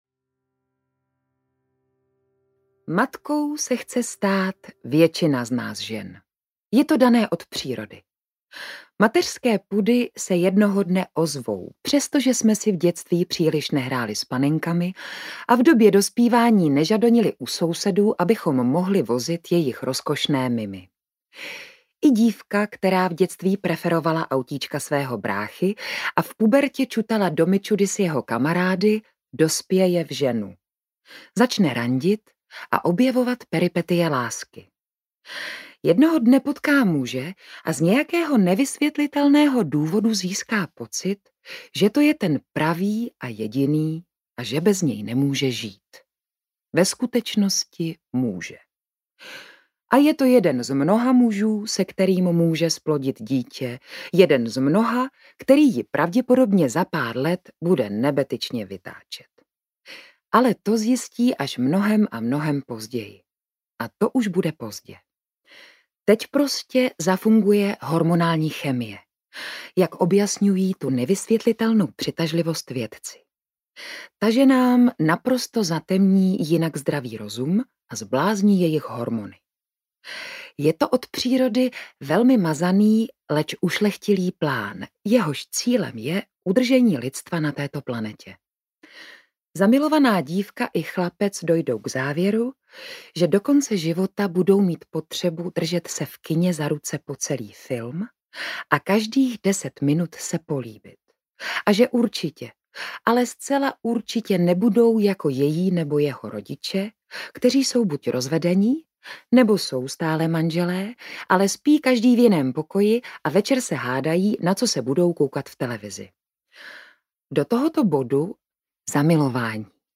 Babičkou proti své vůli audiokniha
Ukázka z knihy